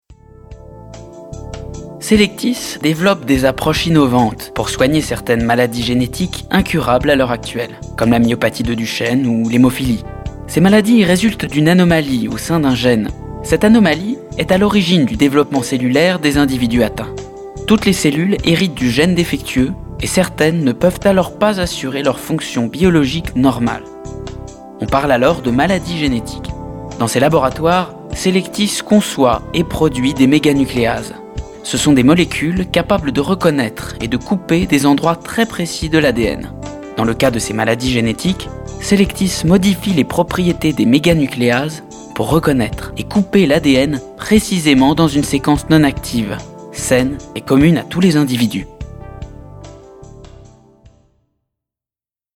Comédien à la voix jeune,medium, très à l'aise dans la comédie mais aussi voice over, documentaire, etc.
Sprechprobe: Industrie (Muttersprache):
My voice is young, dynamic and i'm very much at ease with comedy